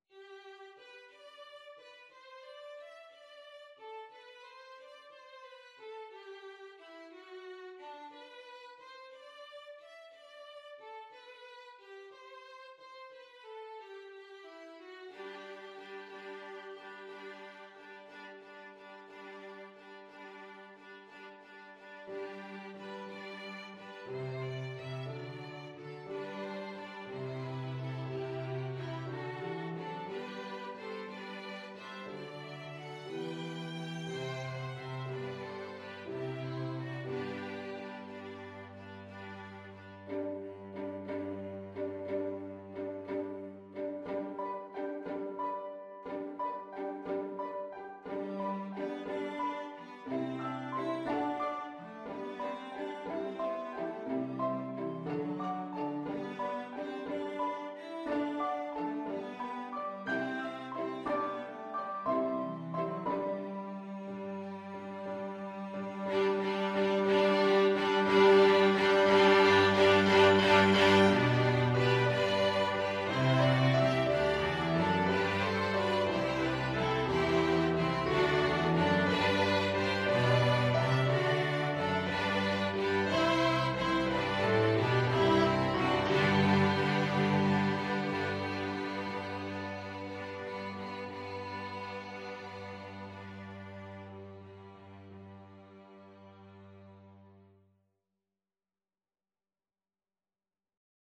Christmas
Violin 1Violin 2ViolaCelloPiano
Quickly and sweetly (. = c. 60)
6/8 (View more 6/8 Music)
Piano Quintet  (View more Easy Piano Quintet Music)
Traditional (View more Traditional Piano Quintet Music)